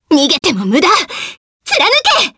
Selene-Vox_Skill8_jp.wav